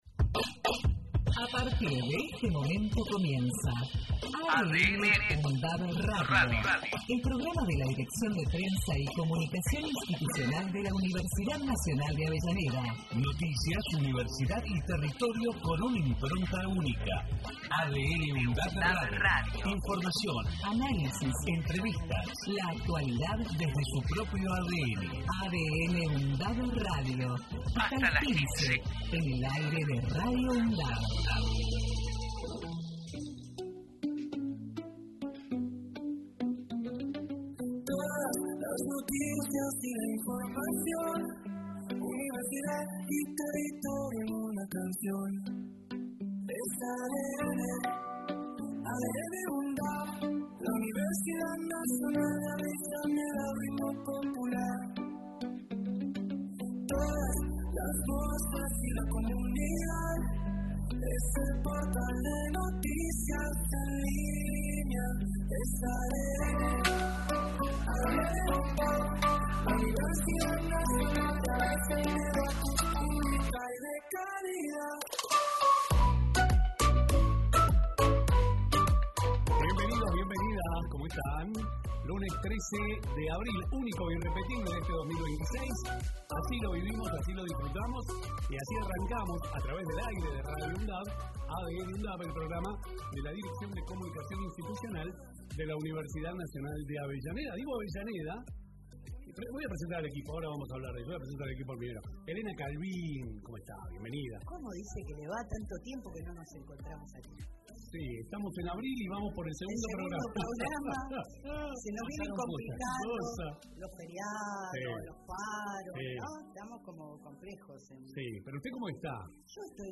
ADN | UNDAV – Radio. Texto de la nota: El programa de la Dirección de Prensa y Comunicación Institucional de la Universidad Nacional de Avellaneda en su emisora Radio UNDAV, busca transmitir la impronta de la Universidad, su identidad, su ADN de una forma actual y descontracturada, con rigurosidad y calidad informativa. Noticias, universidad y territorio son los tres ejes que amalgaman la nueva propuesta a través de la imbricación y la interrelación de las temáticas que ocupan y preocupan a la comunidad local, zonal y nacional desde una mirada universitaria, crítica y constructiva a través de voces destacadas del mundo académico, político, cultural y social.